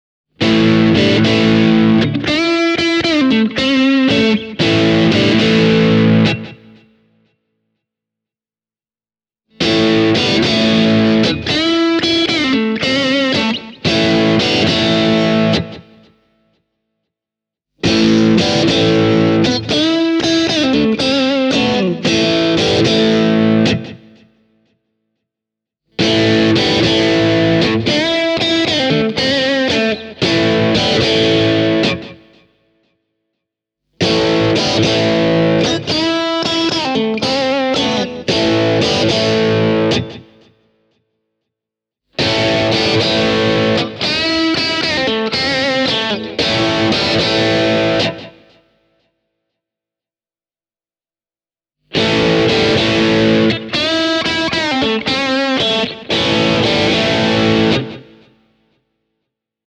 The presence lift in the Vuorensaku set is also easy to spot in distorted sounds. The S. Kamiina’s delivery is a bit more aggressive and in-yer-face, which isn’t a bad thing at all.
For comparison purposes the first (neck pickup) and last (bridge pickup) phrases of the sound clip have been played using my own vintage-style Strat, while the five phrases in between have been recorded with the S. Kamiina set (starting with the neck pickup):
vuorensaku-s-kamiina-e28093-overdriven.mp3